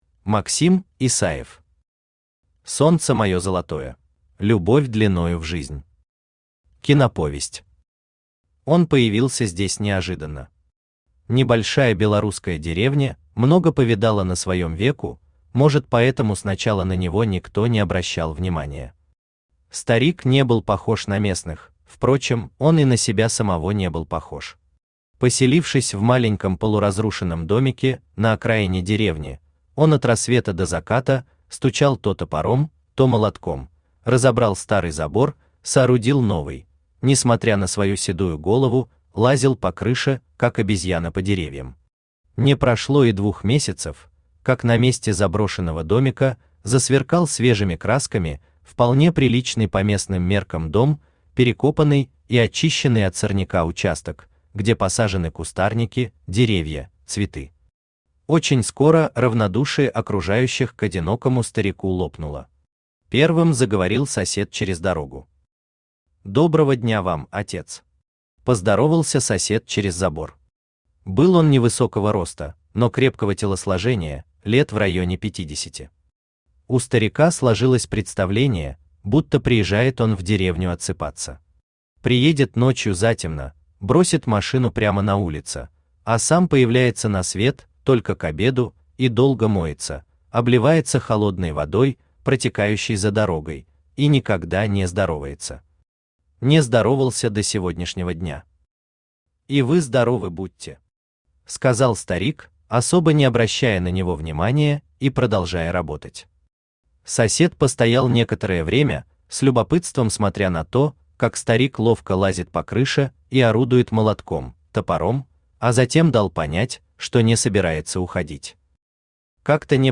Аудиокнига Солнце моё золотое | Библиотека аудиокниг
Aудиокнига Солнце моё золотое Автор Максим Исаевич Исаев Читает аудиокнигу Авточтец ЛитРес.